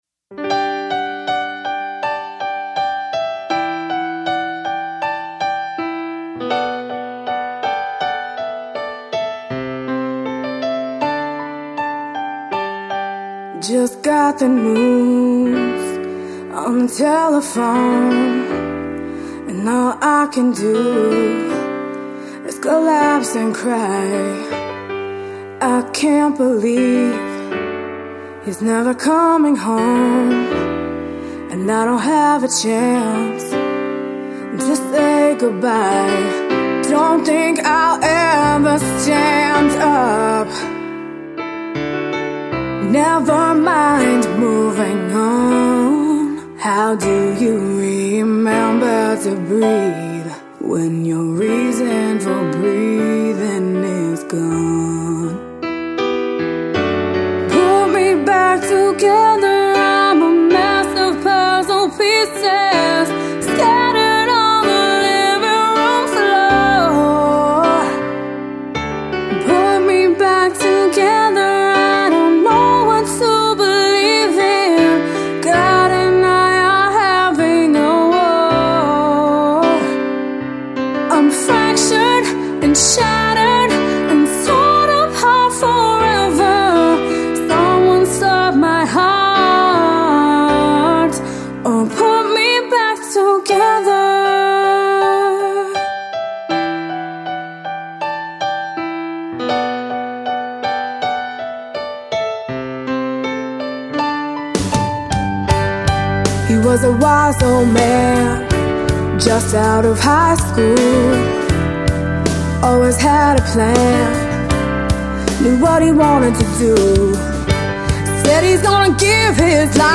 Midtempo Fm vx/piano